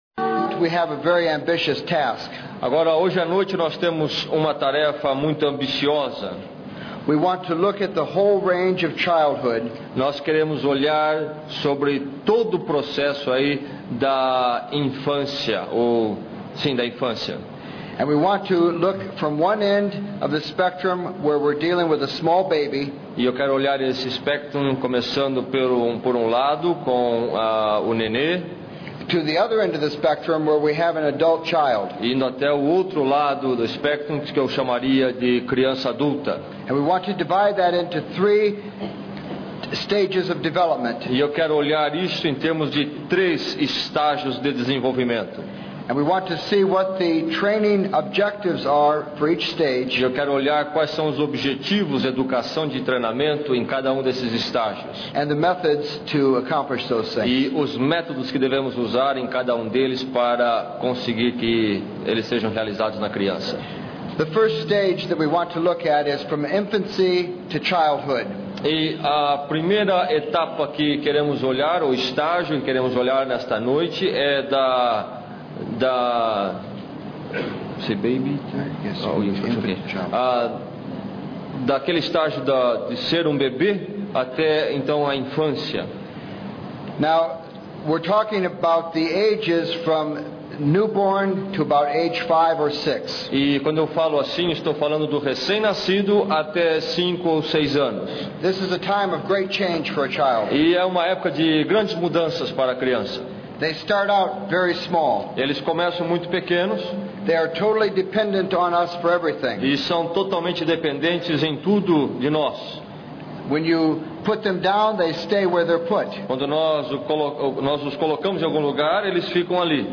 10ª Conferência Fiel para Pastores e Líderes – Brasil - Ministério Fiel
Palestra 1 Palestra 2 Palestra 3 Palestra 4 Palestra 5 Palestra 6 Palestra 7 Palestra 8 Por que Orientar as suas Crianças?